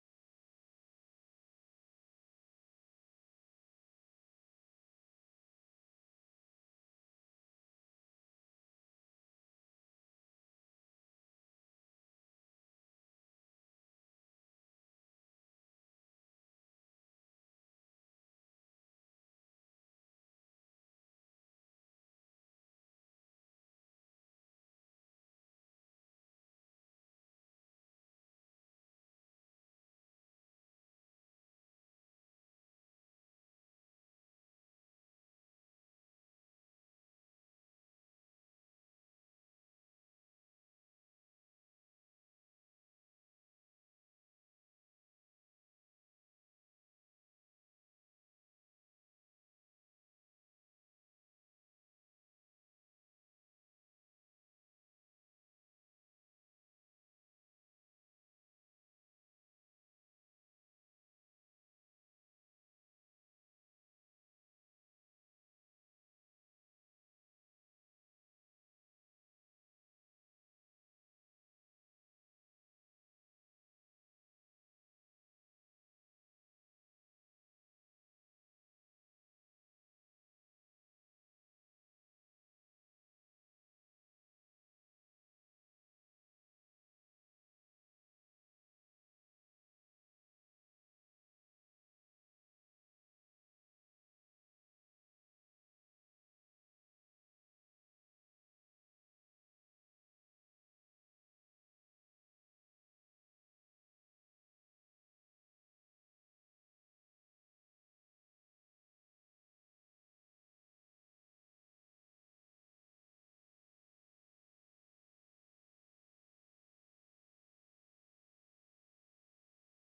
The 7th of July 2024 Sunday Worship